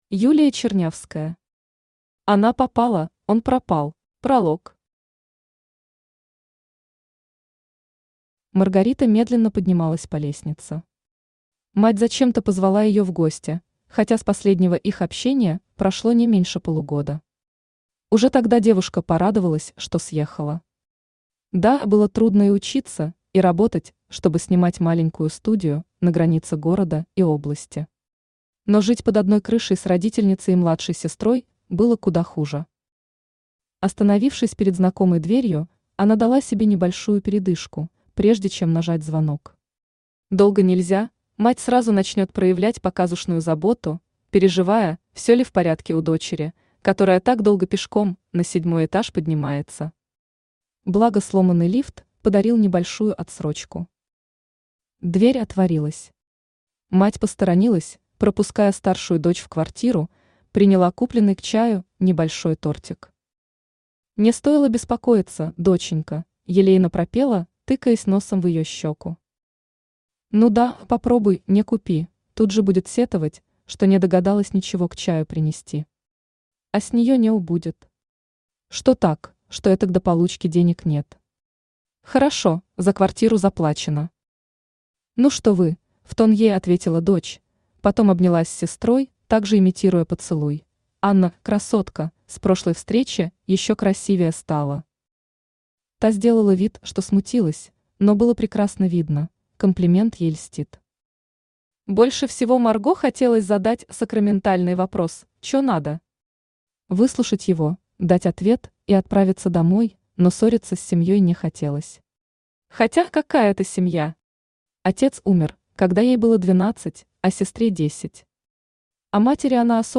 Аудиокнига Она попала – он пропал | Библиотека аудиокниг
Aудиокнига Она попала – он пропал Автор Юлия Вячеславовна Чернявская Читает аудиокнигу Авточтец ЛитРес.